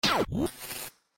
Prototype Hurt Sound Button - Free Download & Play